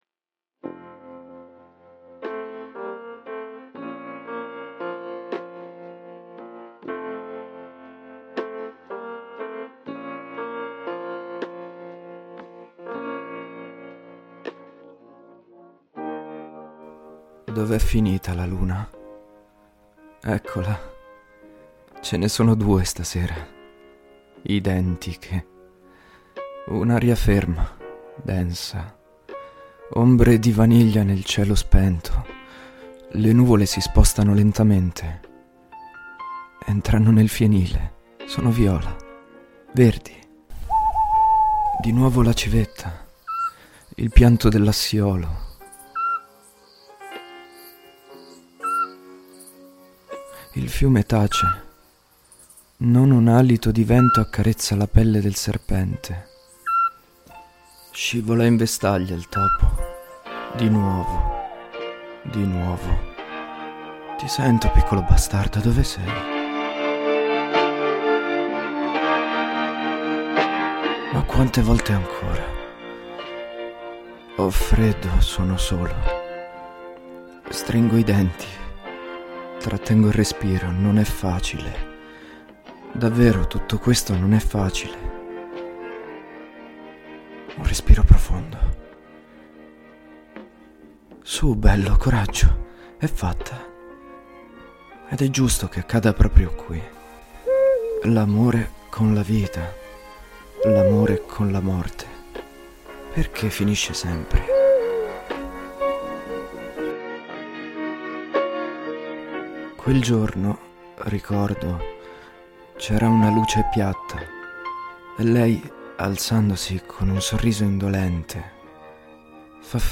This can be considered a page torn from Emmanuel's diary: it is a short delirious monologue, a stream of consciousness in which the boy freely lets his thoughts flow on the wave of depression and unconsciousness, while he is in the barn where he used to go with Antonia.
The soundtrack consists of an instrumental version of Lou Reed's "Perfect Day" (tribute to Trainspotting).